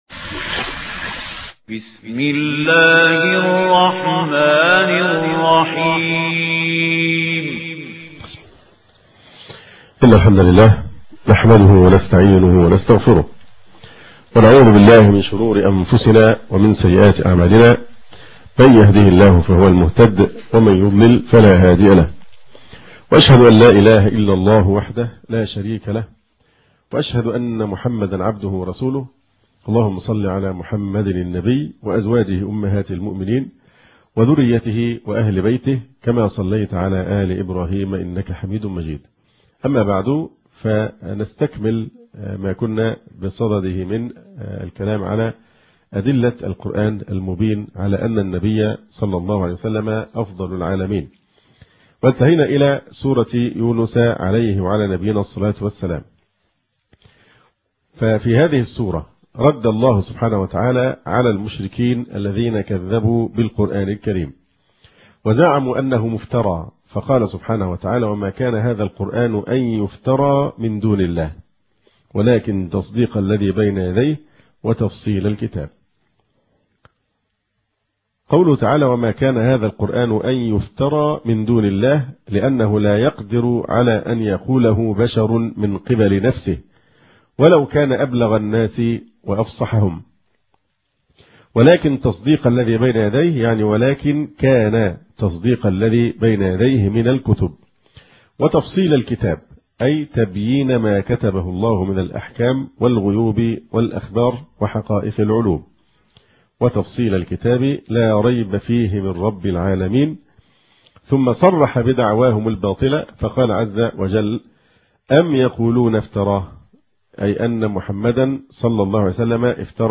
المحاضرة الخامسة - الشيخ محمد إسماعيل المقدم